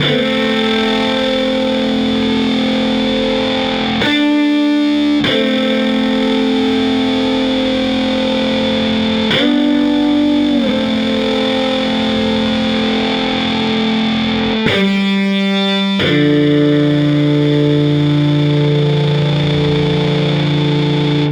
Drone FX 04.wav